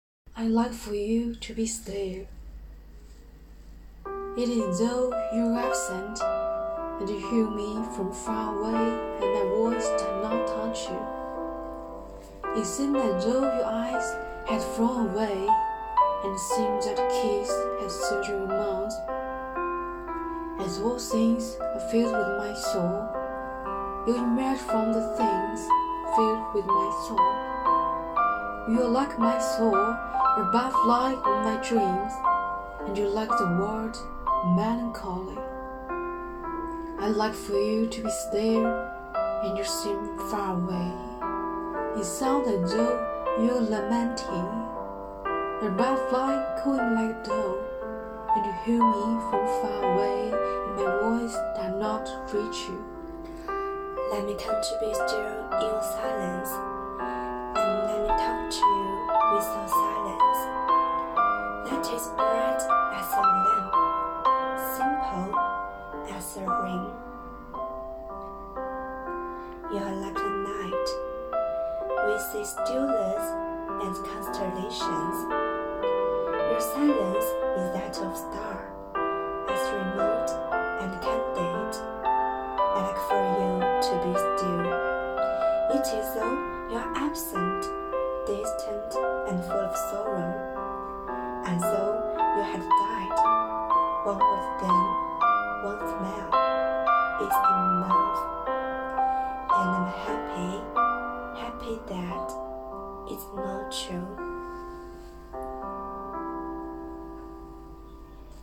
书香校园‖十二月美文诵读欣赏第一期